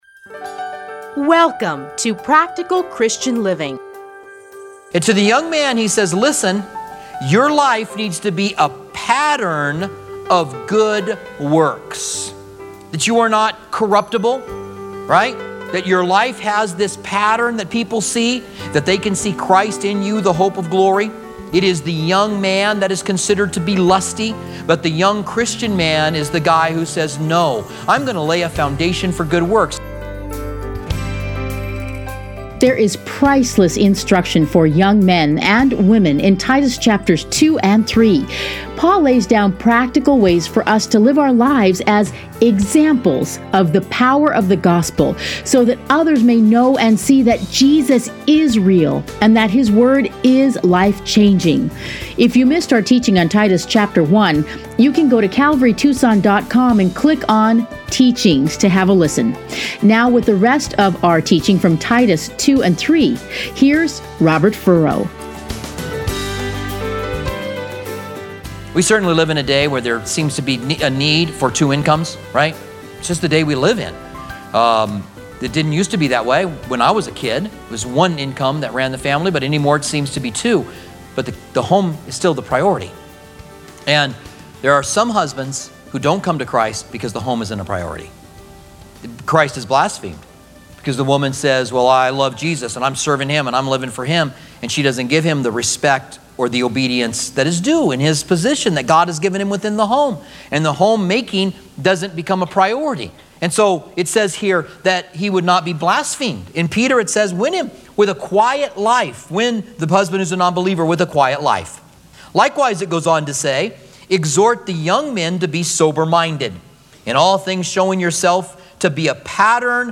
Listen here to a teaching from Titus.